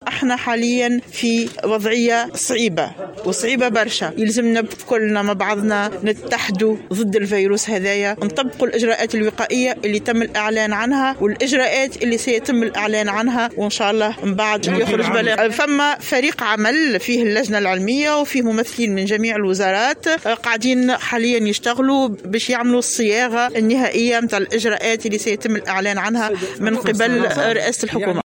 أعلنت الناطقة الرسمية باسم وزارة الصحة نصاف بن علية اليوم الأربعاء أنه سيتم لاحقا الاعلان عن اجراءات جديدة لاحقا.